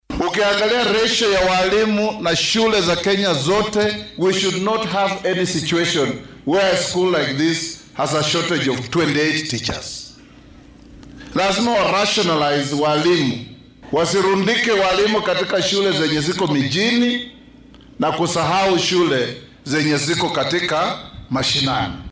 Guddoomiyaha golaha qaran ee xeer dejinta Moses Wetangula ayaa walaac ka muujiyay in ay macallimiin yari ka jirto degmooyinka. Waxaa uu wasaaradda tacliinta ee dalka ugu baaqay in ay isku dheellitirto shaqaaleysiinta macallimiinta . Arrintan ayuu guddoomiyaha baarlamaanka dalka Moses Wetangula ka sheegay ismaamulka Trans Nzoia.